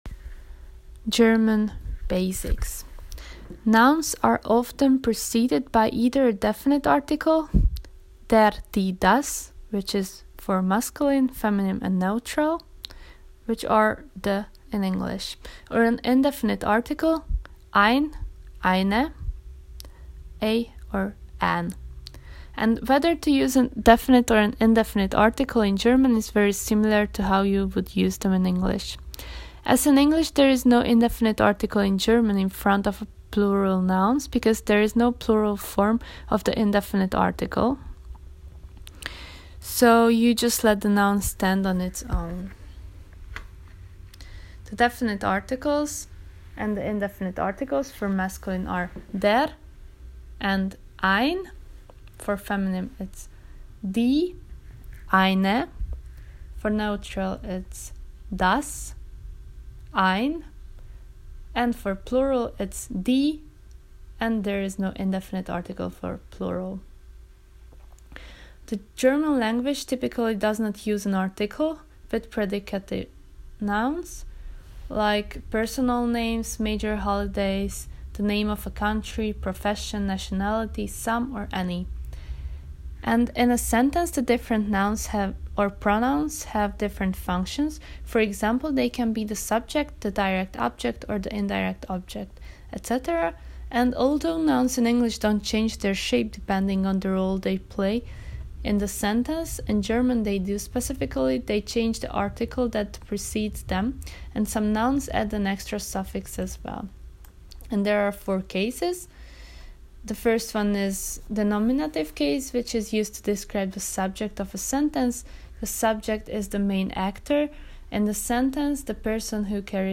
1. Lecture.m4a